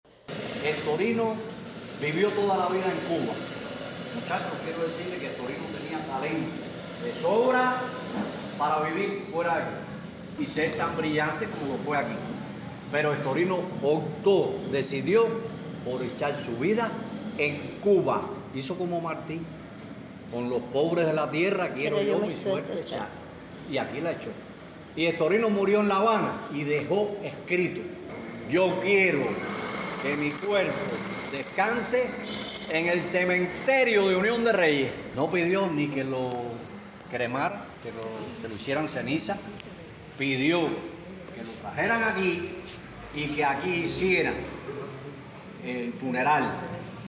En la sala  Pedro Vera, artistas, docentes, educandos y amantes del teatro, se congregaron para recordar la vida y obra de un hombre que dejó una huella imborrable en la escena teatral de la isla.